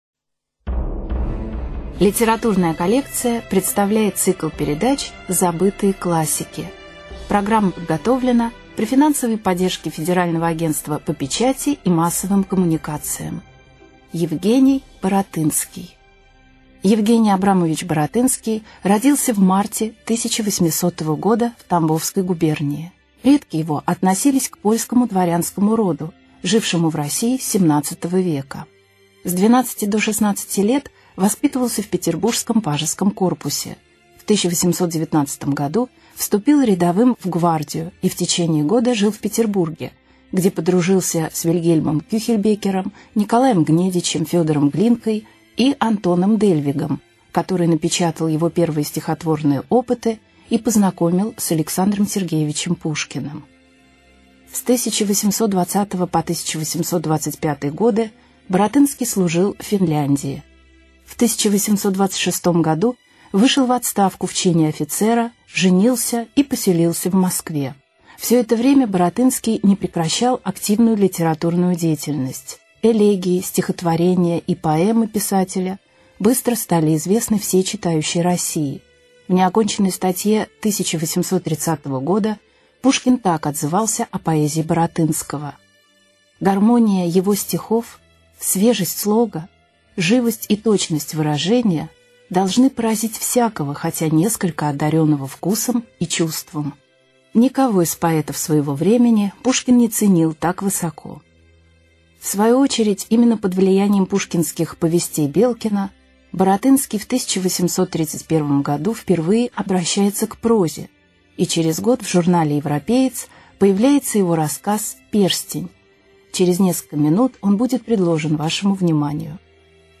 На данной странице вы можете слушать онлайн бесплатно и скачать аудиокнигу "Перстень" писателя Евгений Баратынский. Включайте аудиосказку и прослушивайте её на сайте в хорошем качестве.